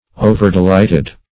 overdelighted - definition of overdelighted - synonyms, pronunciation, spelling from Free Dictionary
Overdelighted \O"ver*de*light"ed\, a. Delighted beyond measure.